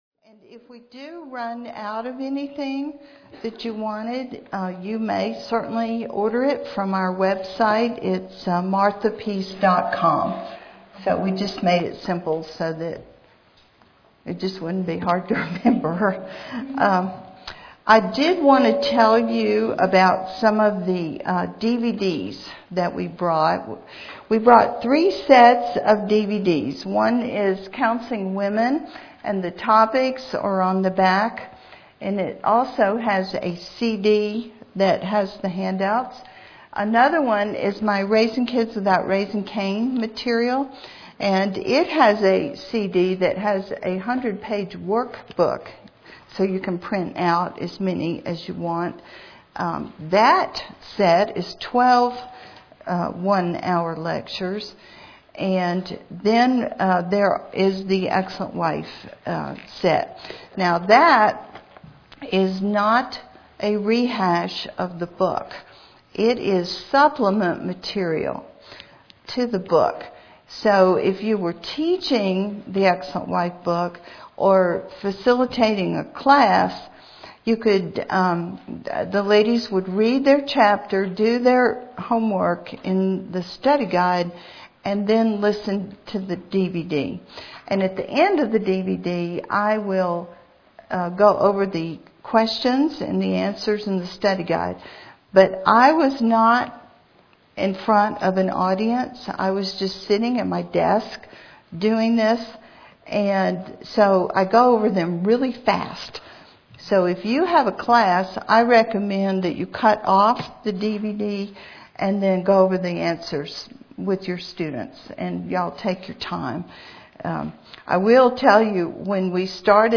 Cornerstone Church and Teton Valley Bible Church Women’s Conference 2011